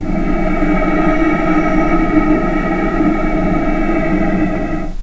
vc-E4-pp.AIF